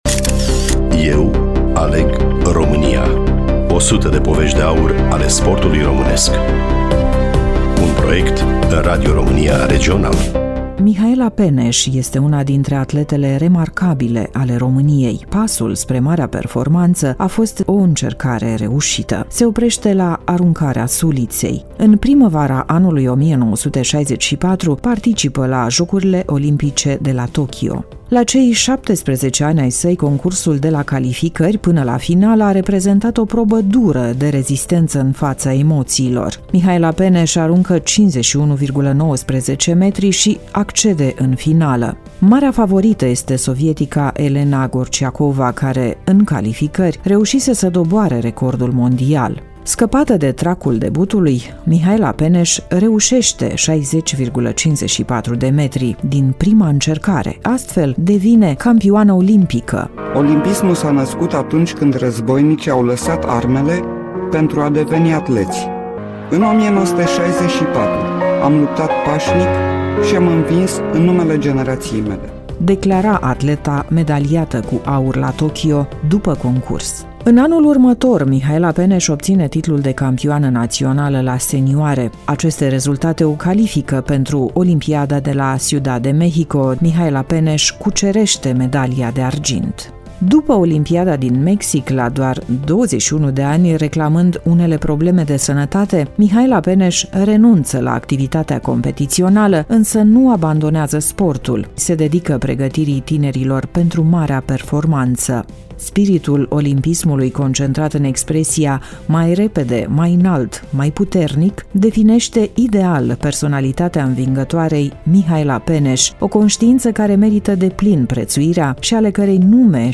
Studioul: Radio Romania Iaşi